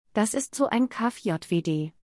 (Please note: the audio pronunciations in this post are in a “Standard German” accent, so they don’t sound exactly as they would coming from a real Berliner.)